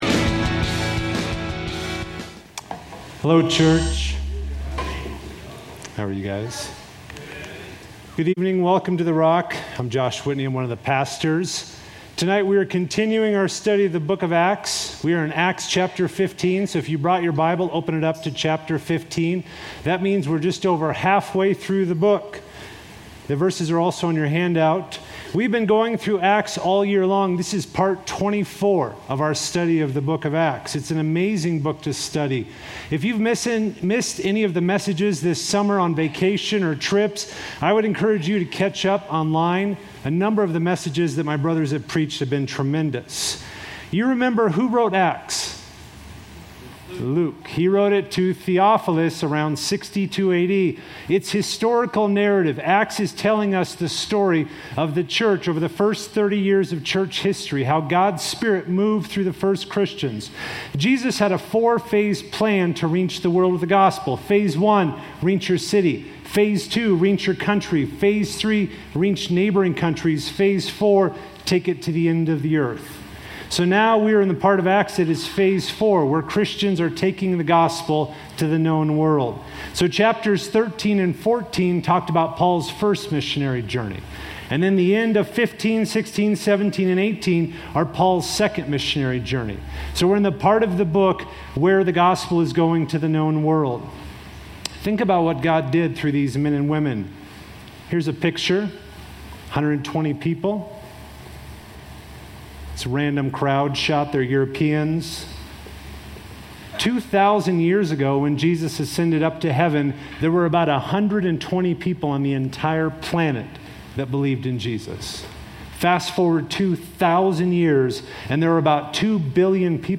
A message from the series "Healthy Living."